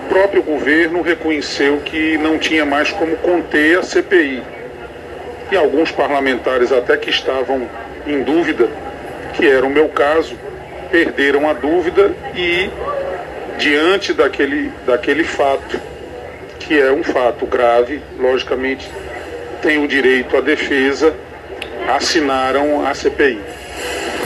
Em entrevista ao programa Arapuan Verdade, da Rádio Arapuan FM desta quinta-feira (20/04), um dos mais recentes parlamentares a entrar com o requerimento, Ruy Carneiro, deu detalhes da decisão.